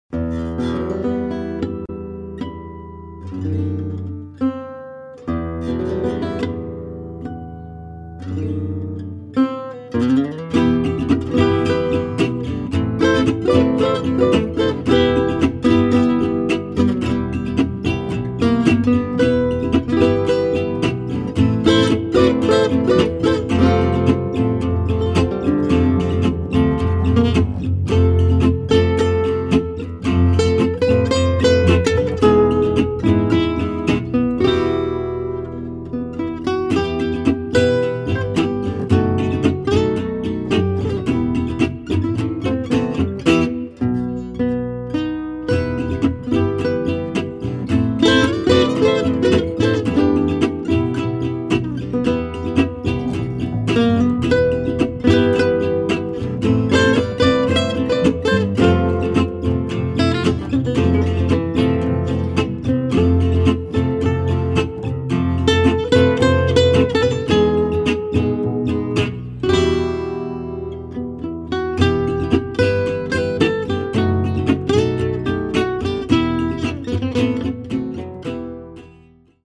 Live!!